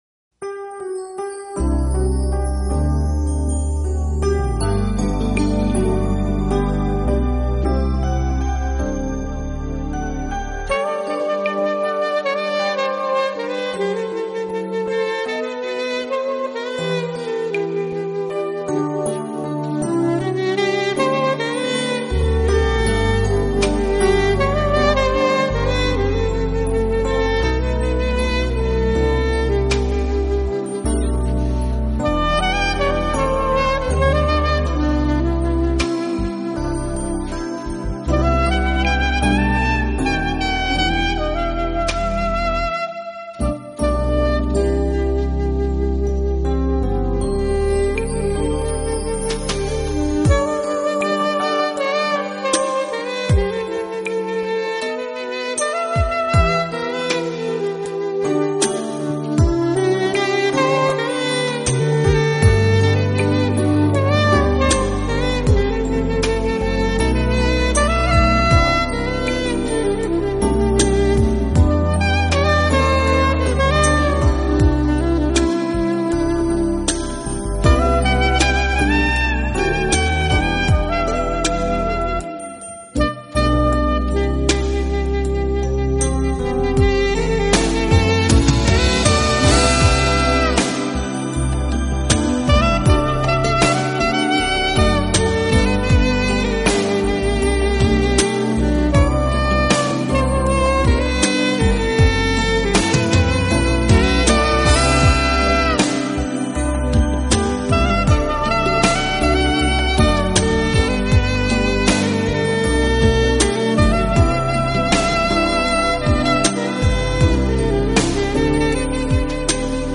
现代爵士，现代R&B乐坛的次中音SAX手。